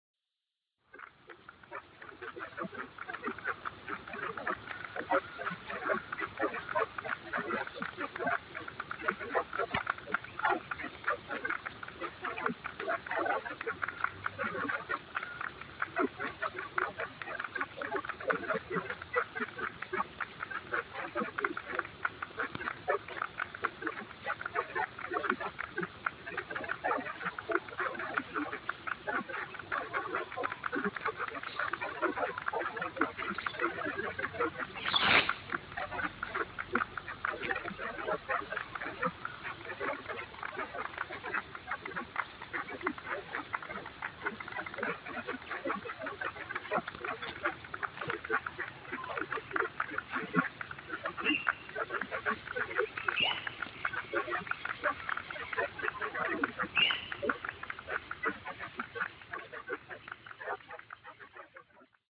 Frogs after rain, Cabbage Tree Creek
Frogs after rain, in Cabbage Tree Creek, Aspley.